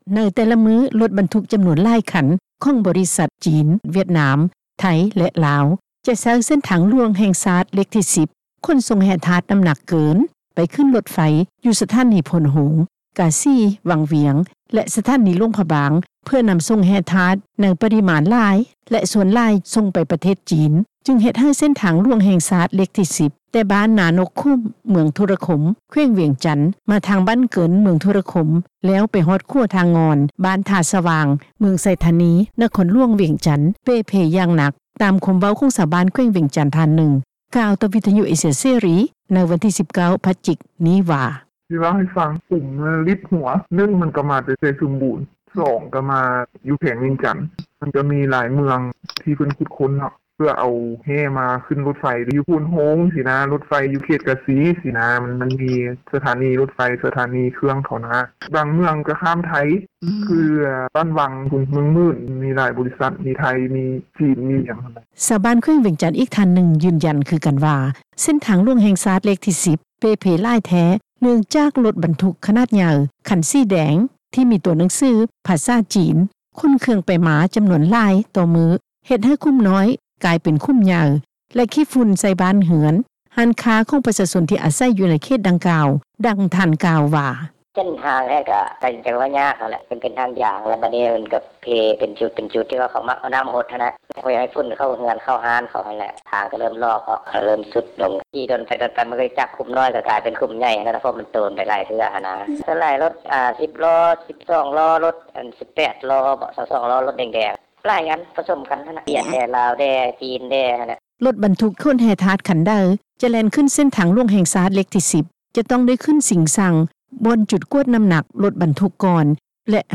ດັ່ງ ເຈົ້າໜ້າທີ່ ກະຊວງໂຍທາທິການແລະຂົນສົ່ງ ທ່ານໜຶ່ງ ກ່າວວ່າ: